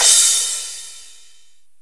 Crashes & Cymbals
pcp_crash05.wav